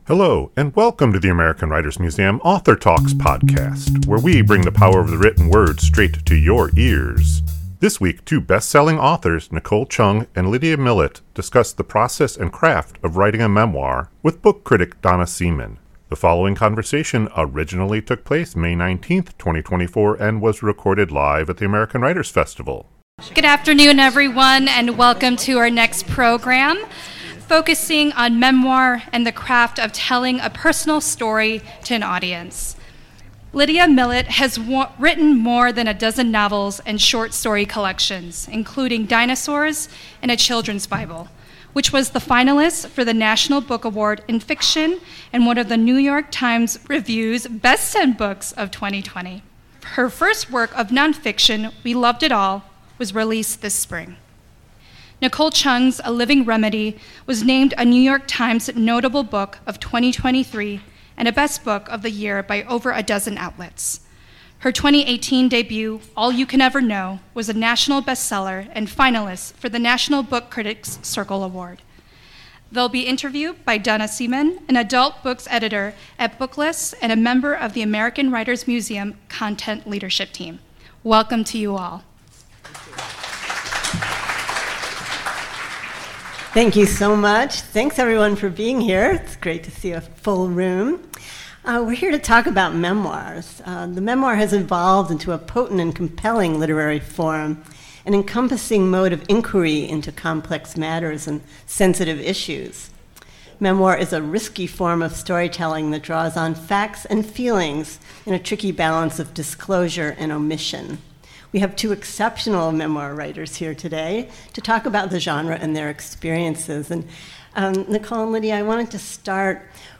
This conversation originally took place May 19, 2024 and was recorded live at the American Writers Festival.